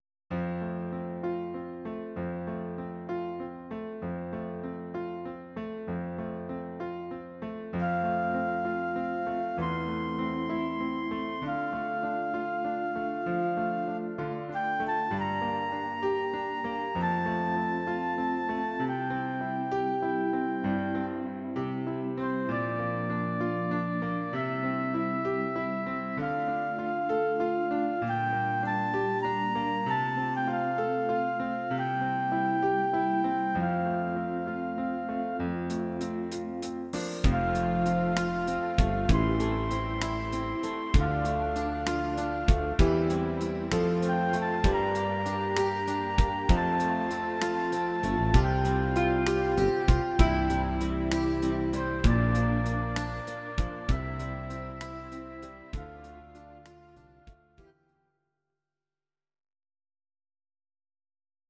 Audio Recordings based on Midi-files
Pop, Duets, 2000s